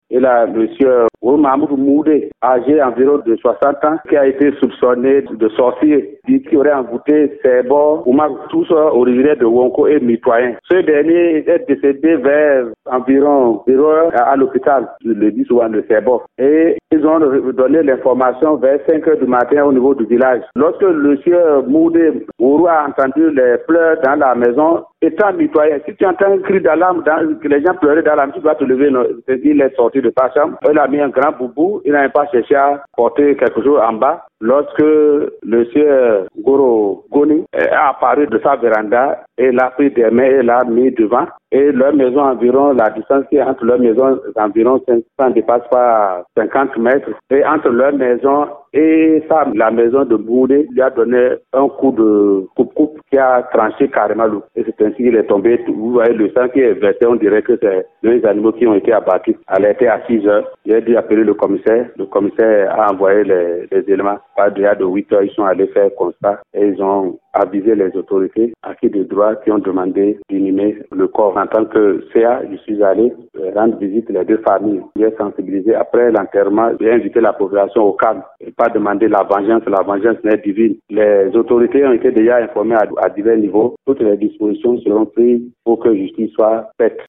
Le CA de Derassi SOUMANOU GUINNIN PIERRE revient sur les circonstances de ce crime. Il a été joint au téléphone.